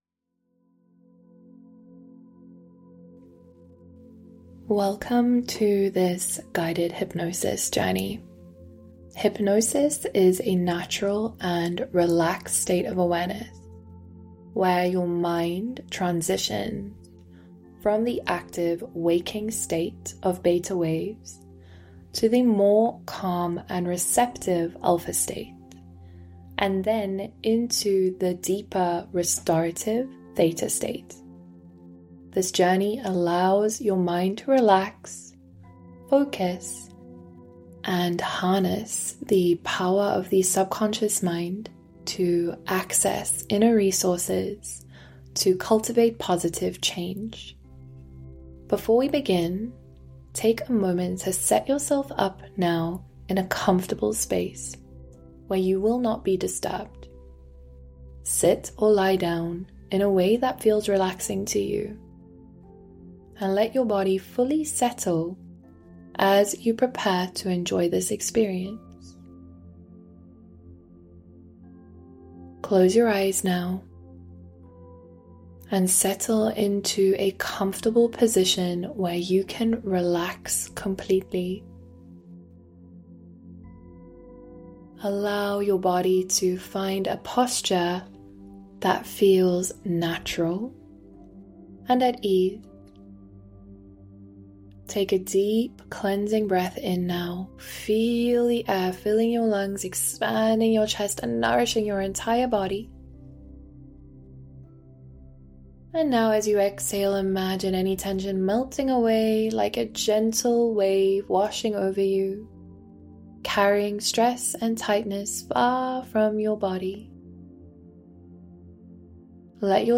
Dive into Your Guided Hypnosis Before you begin your journey to meet your inner Muse, take a moment to create a space where you can relax fully.
awaken_your_inner_muse_hypnosis.mp3